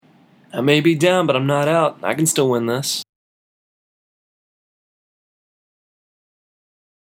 英語ネイティブによる発音は下記のリンクをクリックしてください。